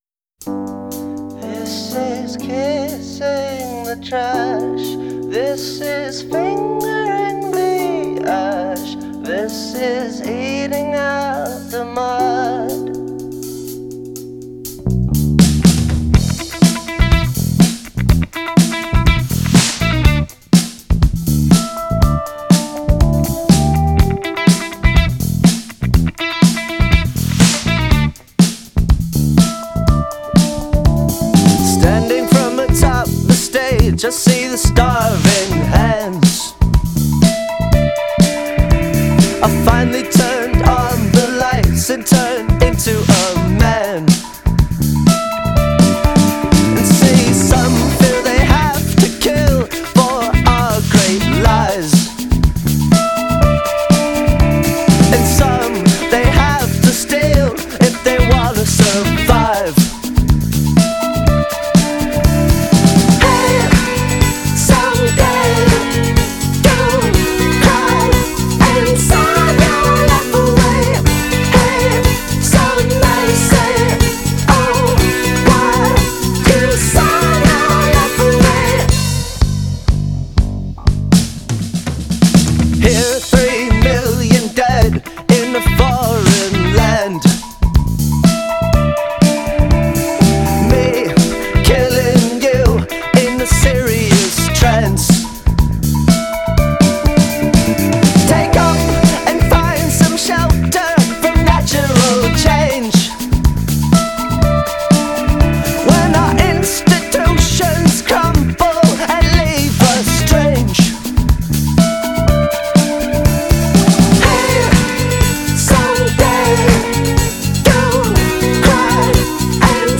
Жанр: Rock, Pop , Indie Rock